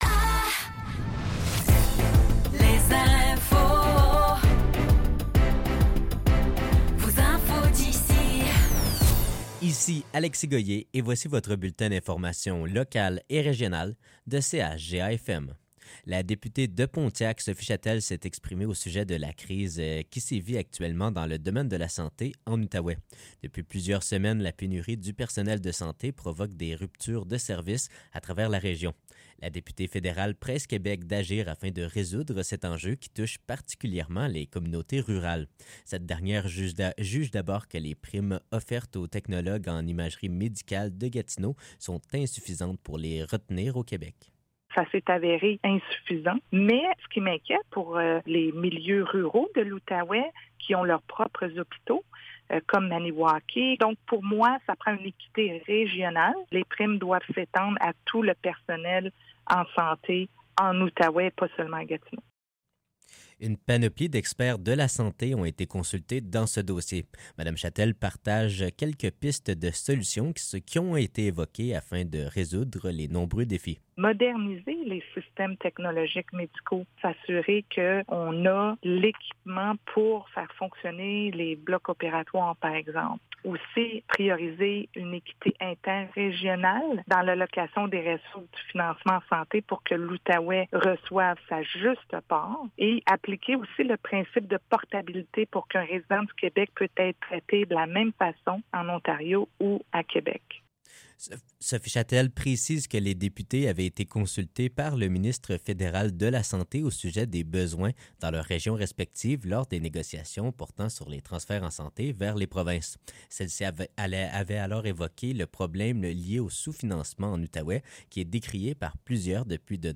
Nouvelles locales - 23 mai 2024 - 15 h